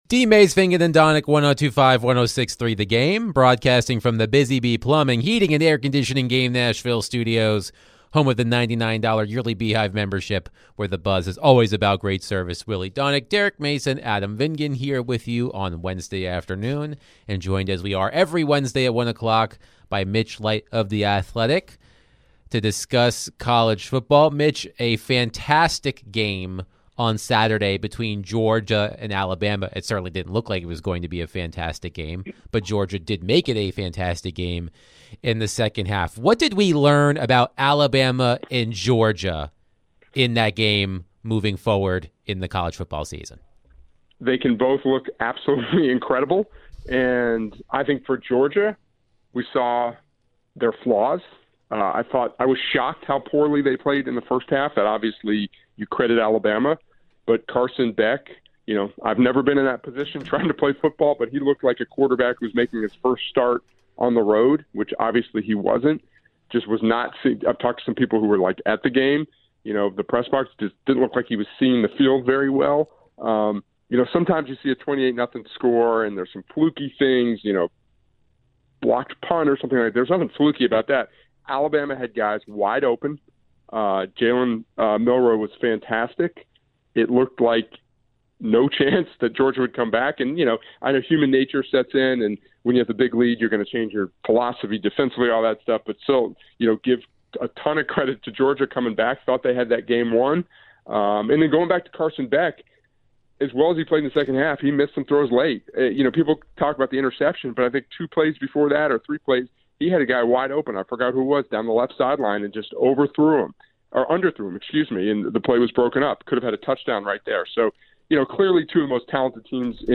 To end the show, the guys answered some calls and texts about the Titans and WIll Levis. Can Will Levis improve as the season continues?